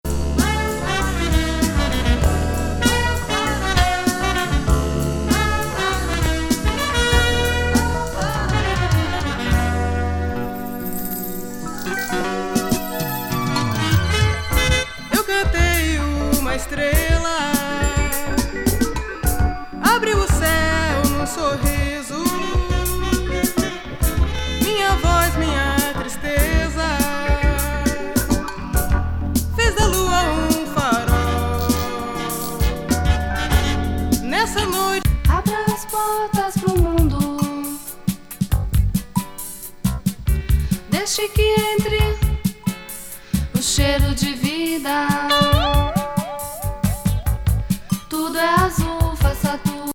83年、ブラジルの学生バンド・コンテスト入賞記念的（たぶん・・）コンピレーション。
若々しいサウンド満載！レア・ブラジリアン・ソウル的なのやムードMPB等！！